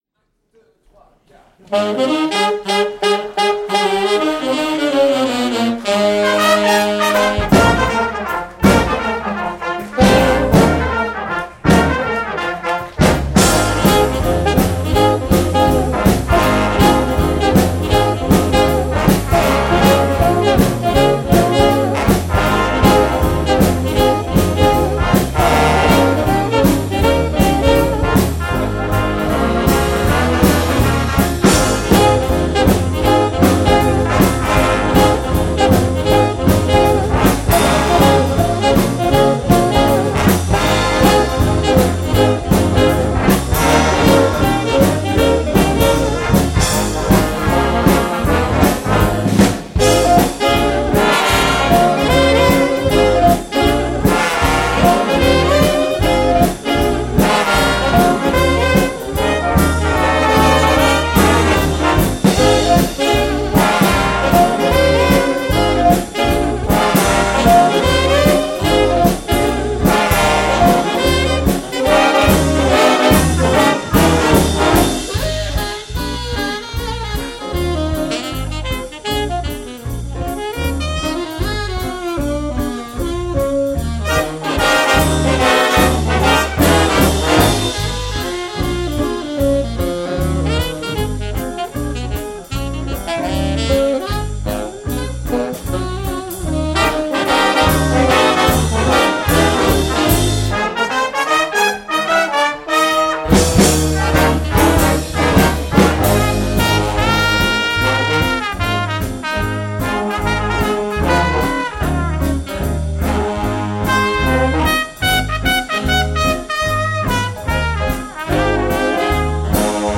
Soirée Jazzalauna 2011 – Samedi 19 Novembre 2011
Ray Big Band – Jazzalauna